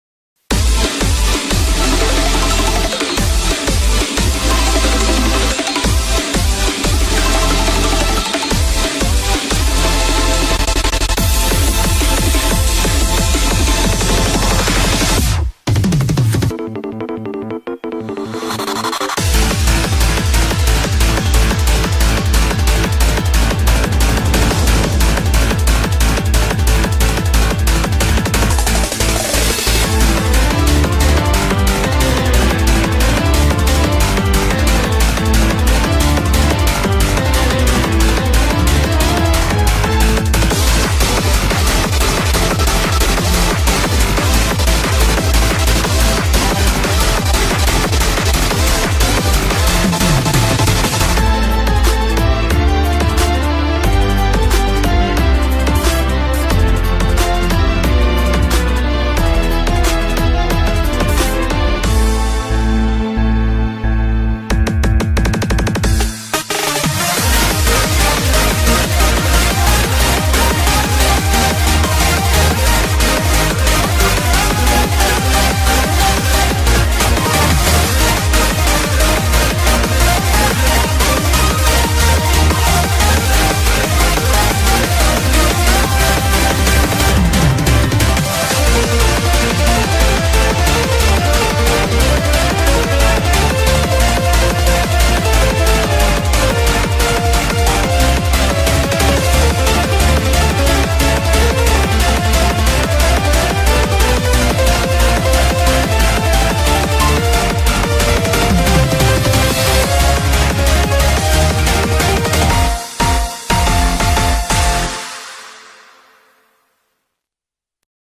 BPM180
Audio QualityPerfect (Low Quality)